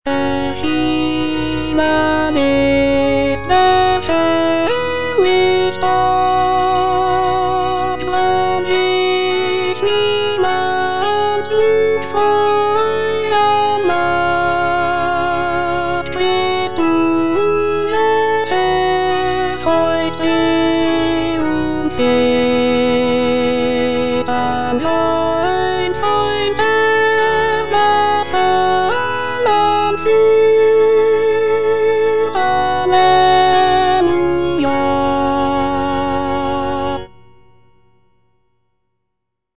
Alto (Voice with metronome) Ads stop